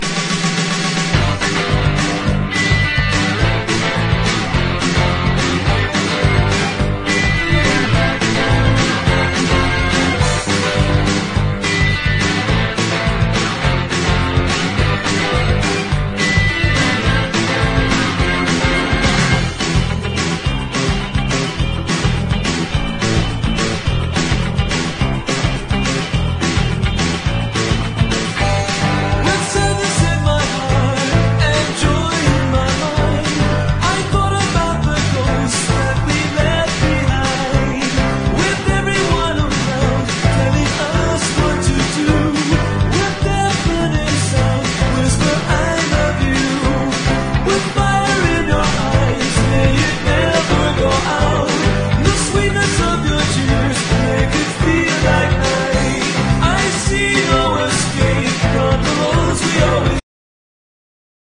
¥880 (税込) ROCK / 80'S/NEW WAVE.